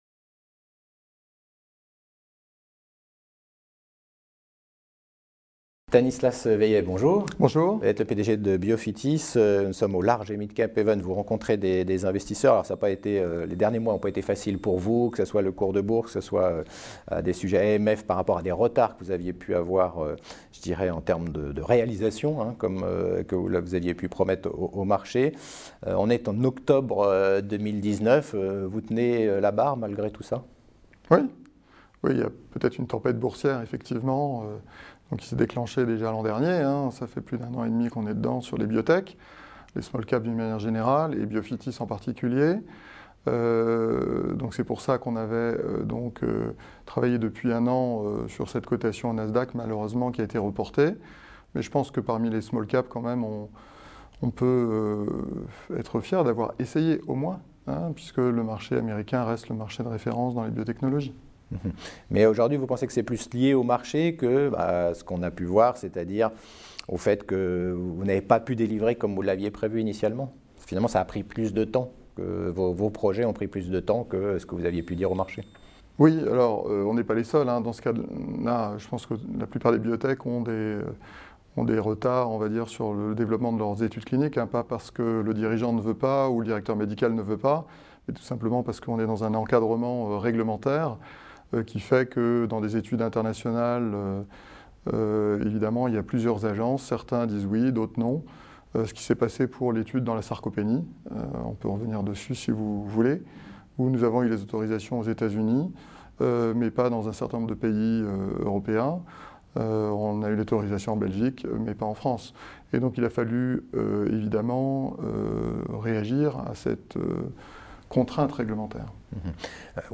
La Web Tv rencontre les dirigeants au Paris Large et Midcap Event 2019.